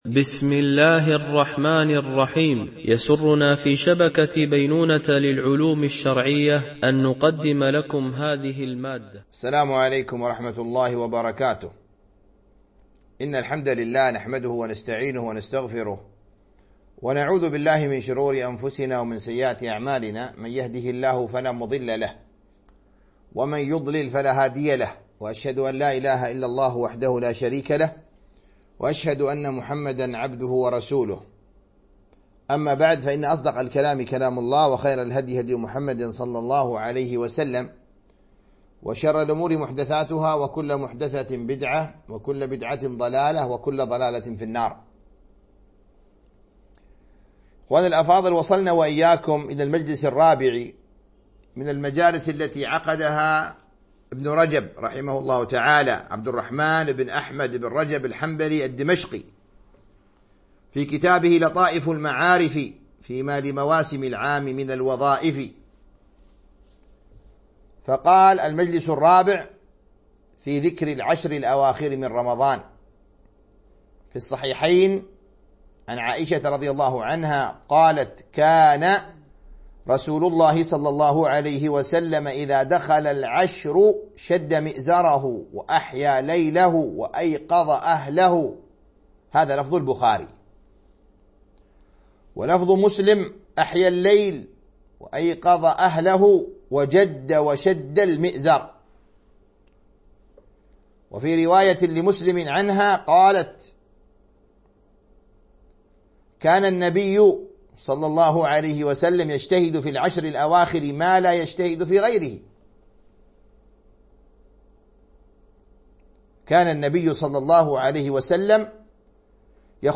التعليق على كتاب الصيام من لطائف المعارف - الدرس 07